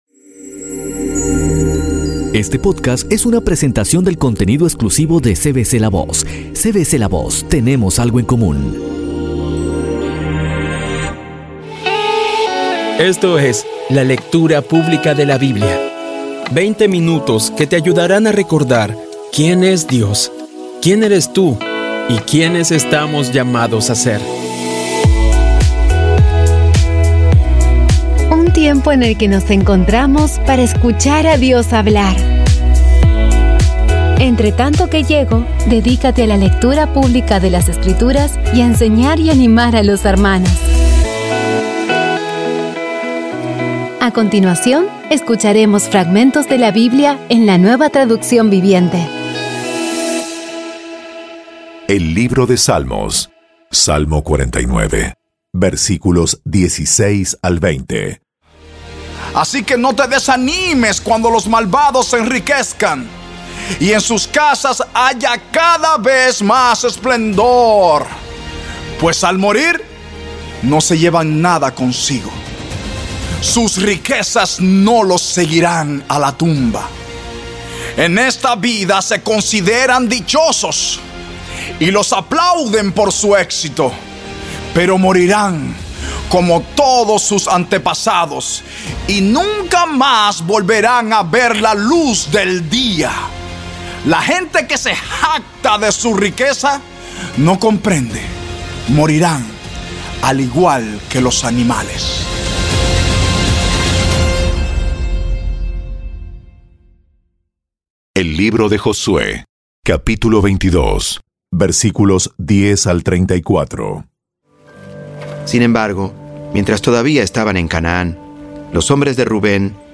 Audio Biblia Dramatizada Episodio 111
Poco a poco y con las maravillosas voces actuadas de los protagonistas vas degustando las palabras de esa guía que Dios nos dio.